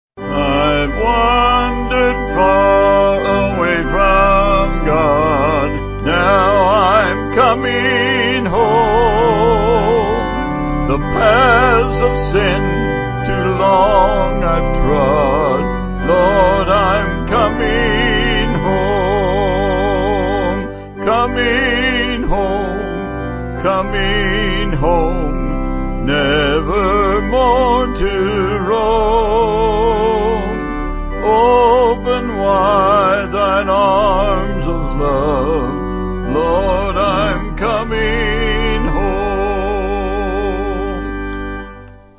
Vocals & Organ